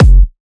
edm-kick-78.wav